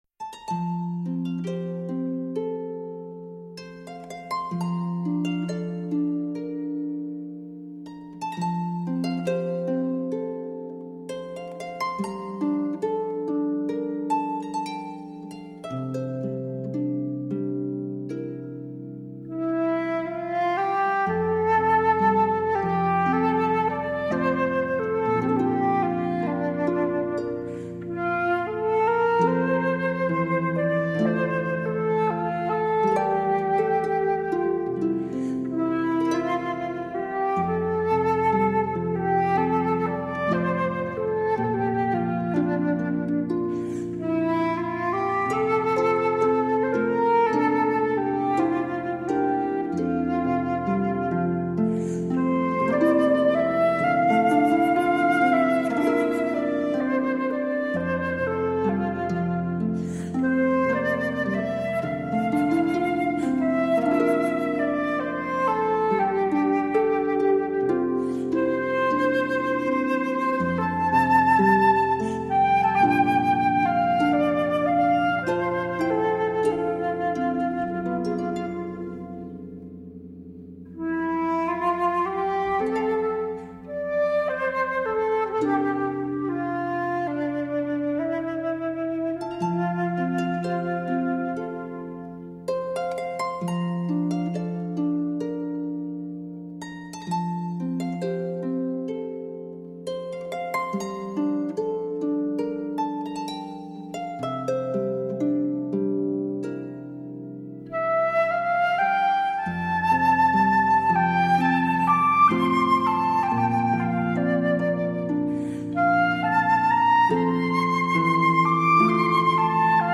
竖琴与长笛的完美演绎 这是一首传唱了一个多世纪的爱尔兰民谣，作者已无从查证，据说是根据一位父亲对即将出征的儿子所写的一封家书而谱的曲。经过了诸多音乐大师的传颂形成了很多版本，本人最喜爱的就是这首竖琴与长笛的版本。
（因为是乐曲所以就不附歌词了）